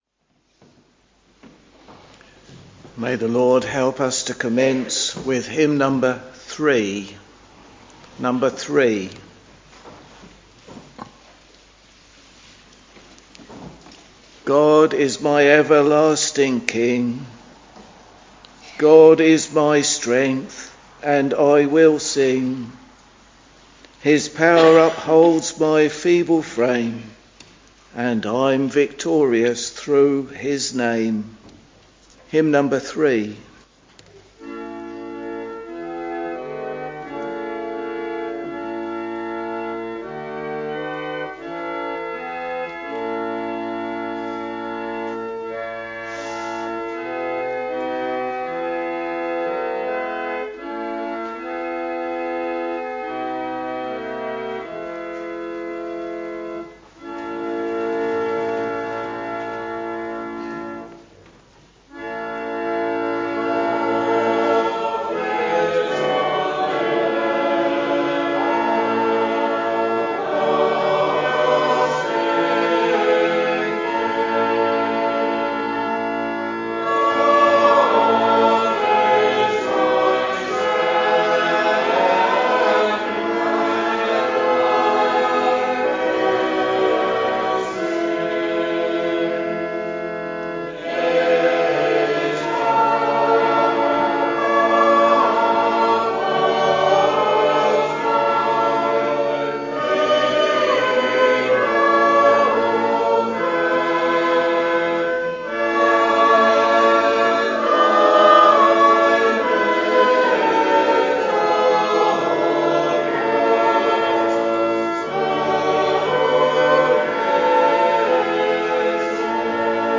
Sunday, 10th November 2024 — Morning Service Preacher